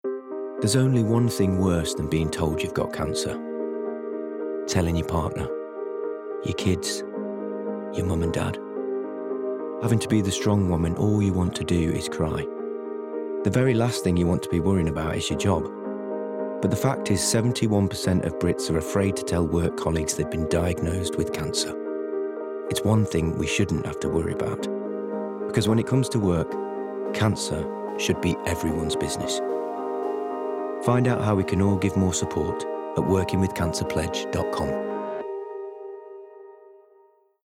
30/40's Light Midlands/US,
Comedic/Expressive/Versatile